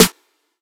Snares
FGGH_SNR.wav